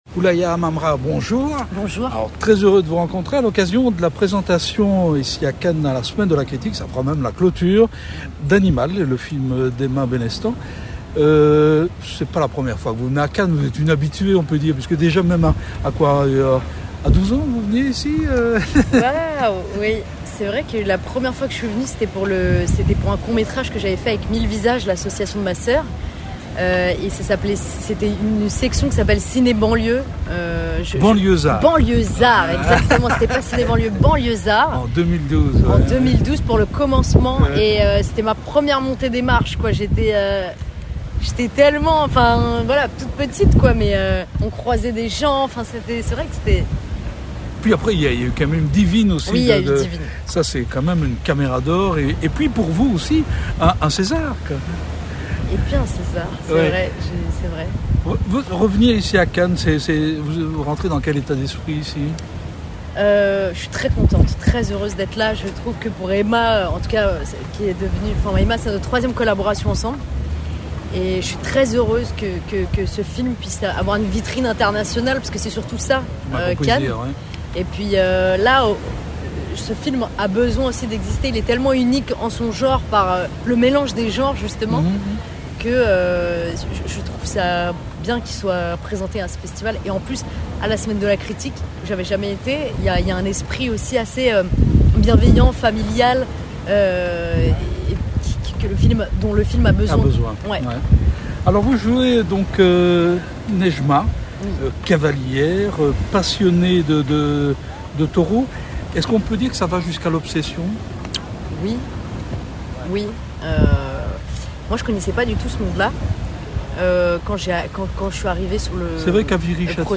Rencontre avec la jeune comédienne qui incarne avec énormément d’émotion cette figure symbolique de la femme en lutte contre cette société patriarcale…